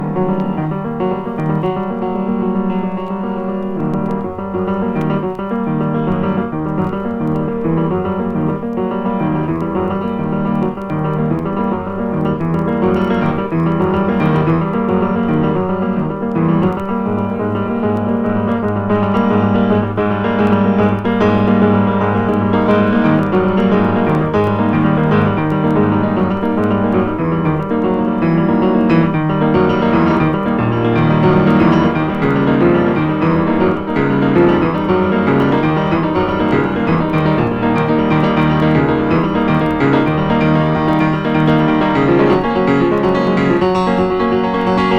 本盤は、ピアノソロ作品集。
ピアノソロとはいえ、奇妙奇天烈っぷりはしっかり刻まれてます。